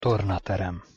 Pronunciation Hu Tornaterem (audio/mpeg)